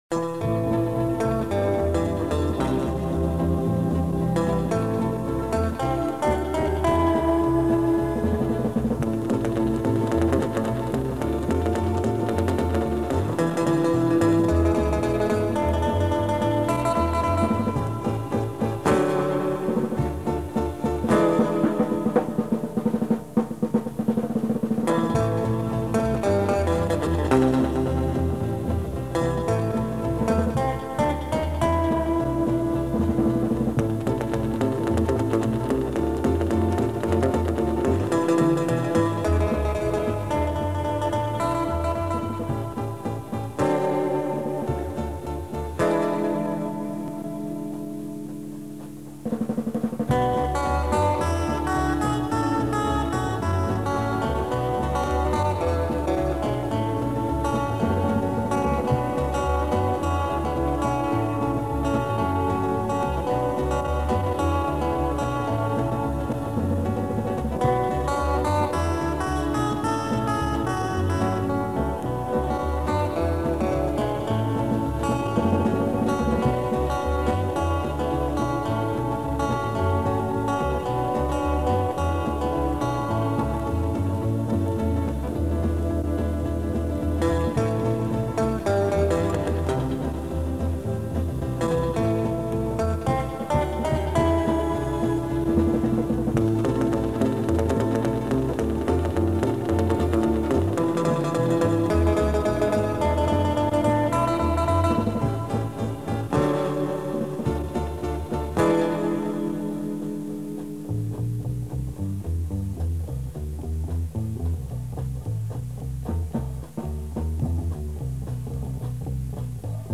instrumentale låter
I mars 1965 spilte vi inn ni melodier på bånd